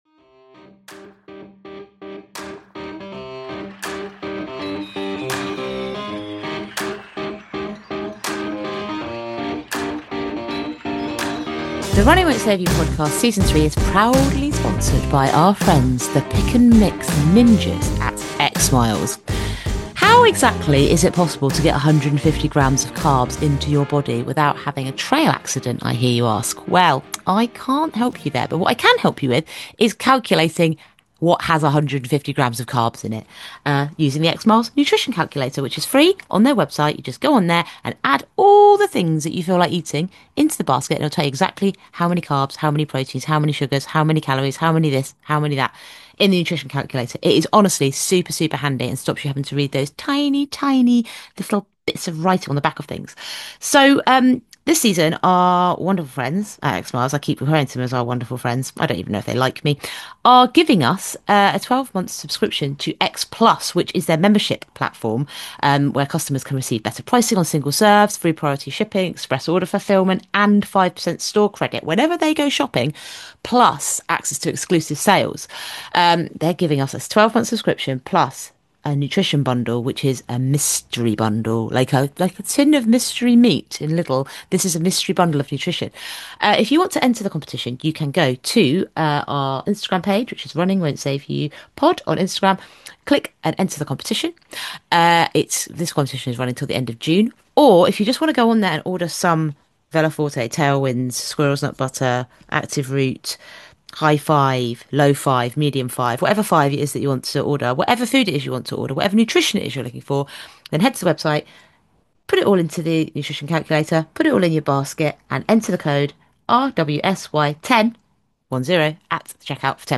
Expect plenty of laughs, sleep-deprived ramblings, and a fascinating insight into ultra-endurance racing.